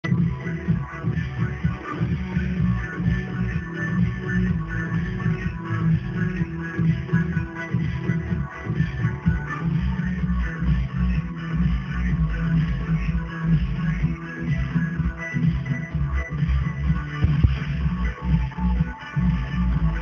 Drunk house tune - please help